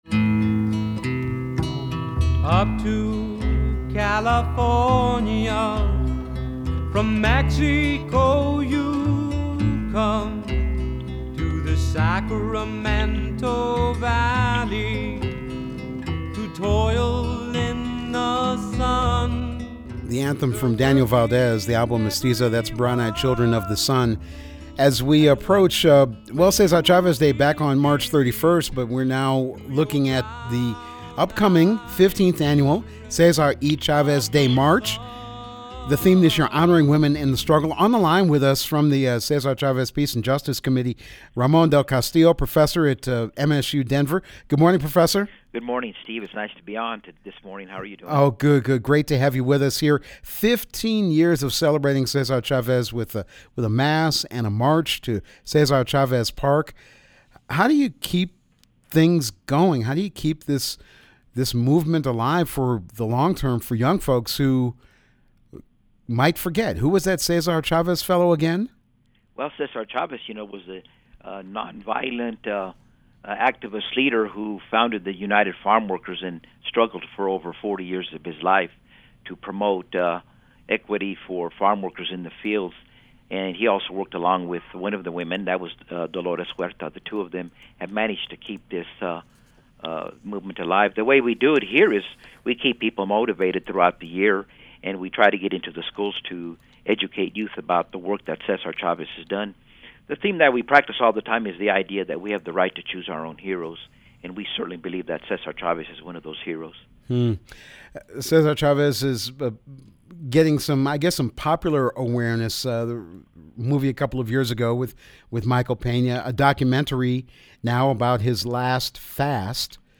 Music on this feature includes Daniel Valdez, “Brown-Eyed Children of the Sun,” from the CD Mestizo.
interview